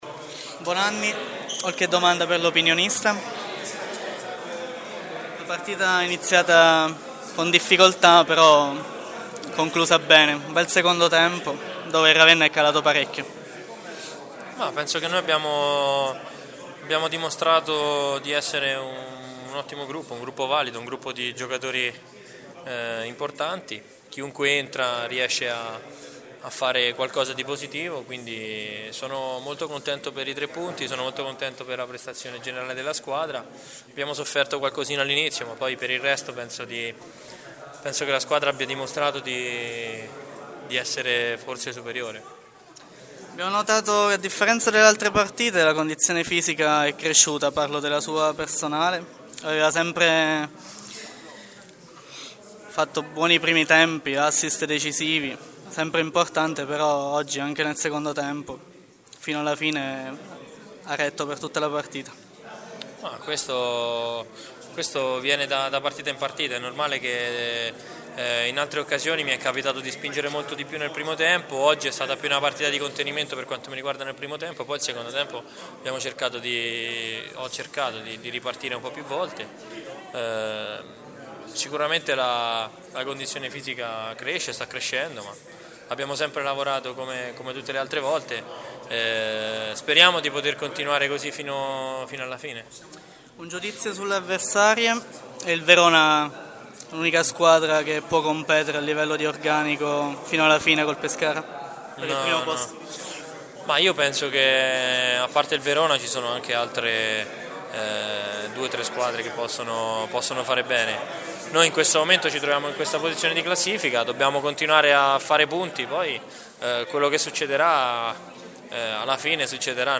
Interviste dopo partita: Pescara-Ravenna